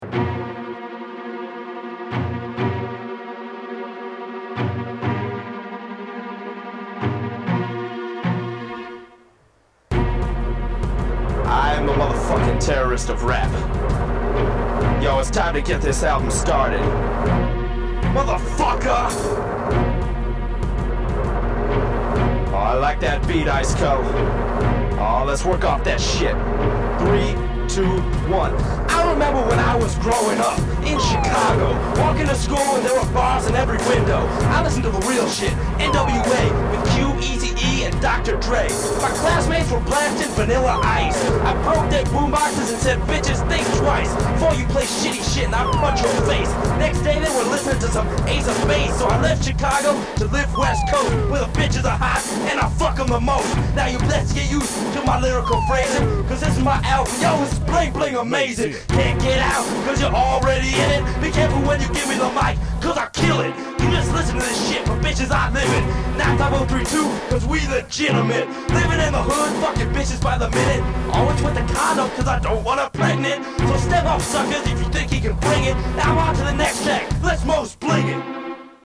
This album has profuse swearing.